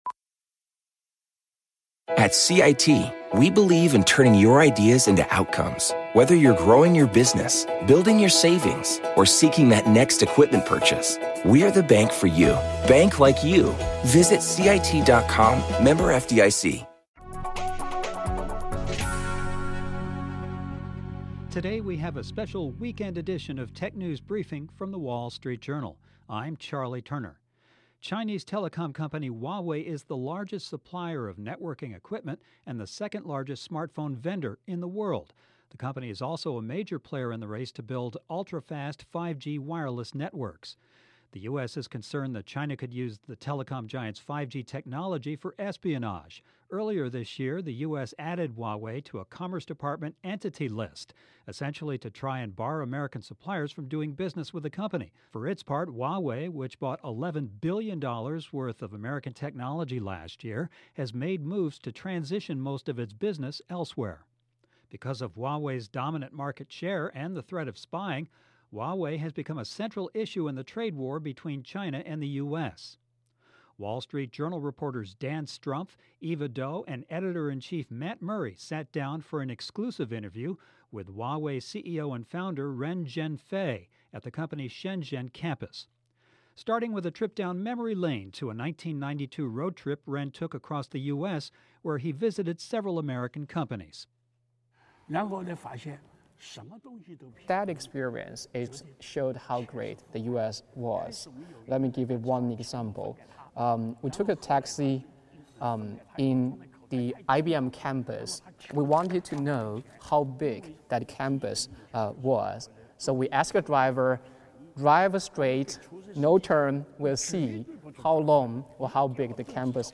SPECIAL: WSJ Exclusive Interview with Huawei CEO Ren Zhengfei
The WSJ recently sat down for an exclusive interview with Huawei founder and CEO Ren Zhengfei in Shenzhen. In this special, Ren talks about how the US blacklisting has impacted Huawei - and why he says Huawei doesn't need the US to survive.